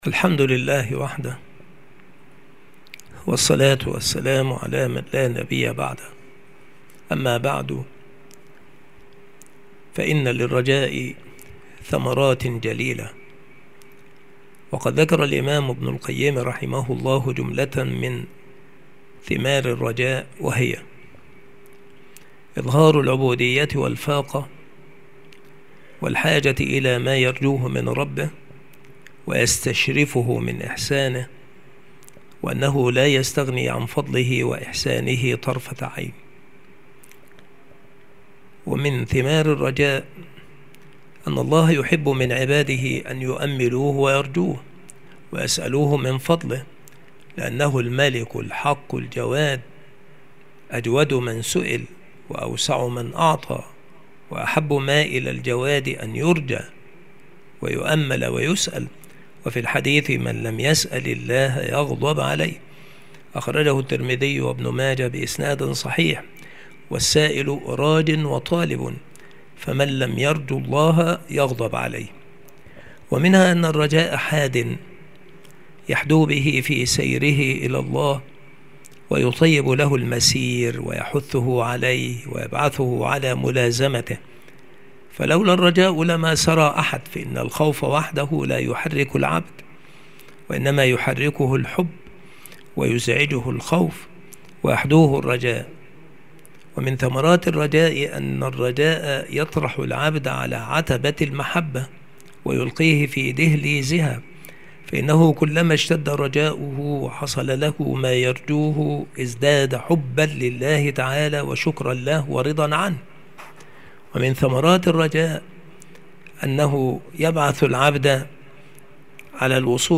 مكان إلقاء هذه المحاضرة المكتبة - سبك الأحد - أشمون - محافظة المنوفية - مصر عناصر المحاضرة : من ثمرات الرجاء.